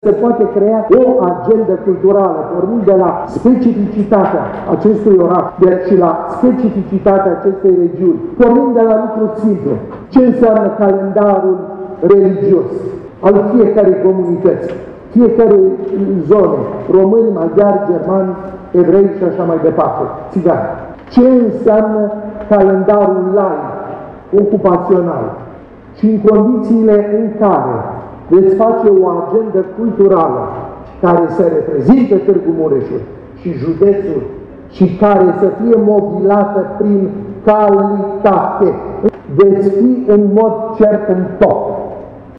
Conferința internațională „JUDEȚUL MUREȘ – BRAND LOADING” a fost dedicată unor invitați din țară și din străinătate, care au vorbit despre felul în care văd județul Mureș și despre oportunitățile pe care acesta le are, pentru crearea unui brand de regiune.